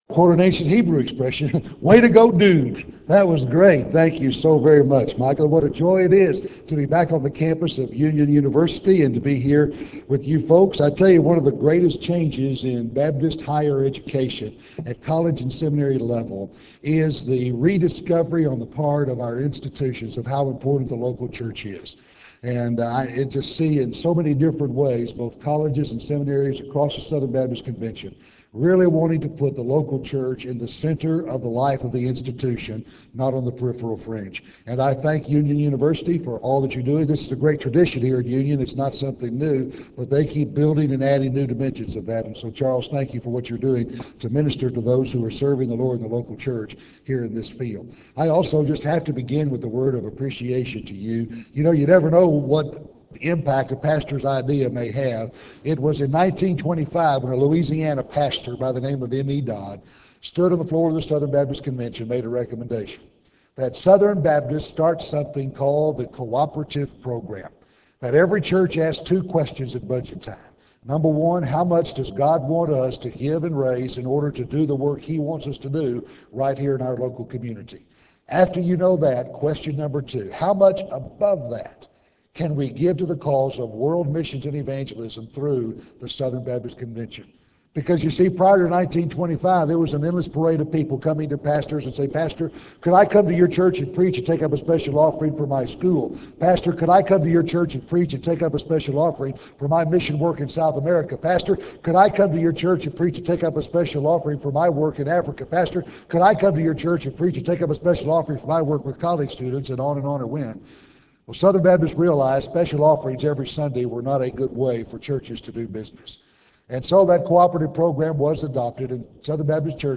Address: "The Big Picture"